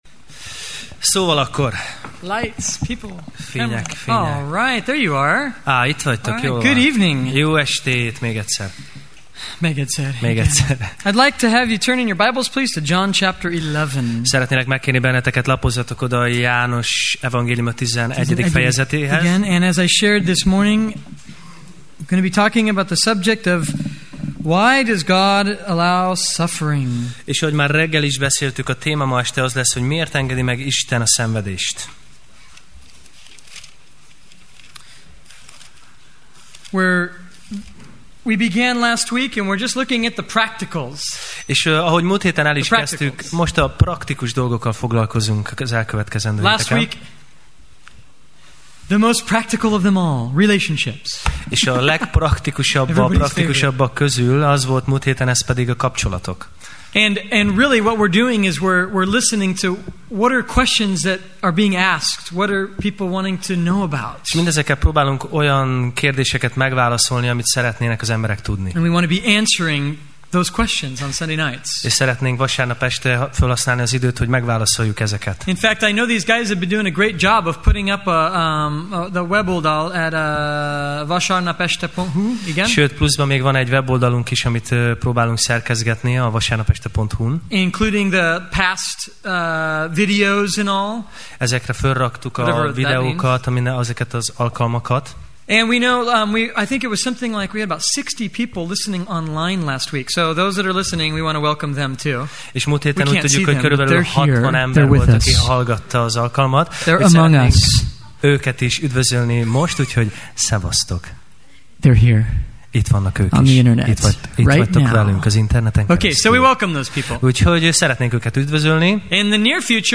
Sorozat: Tematikus tanítás Alkalom: Vasárnap Este